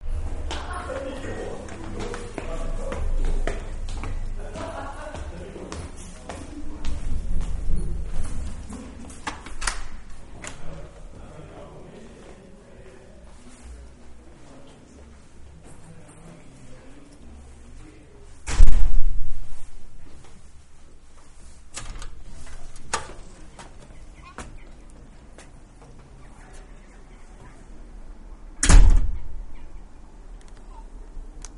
Descarga de Sonidos mp3 Gratis: ambiente 7.
puerta_5.mp3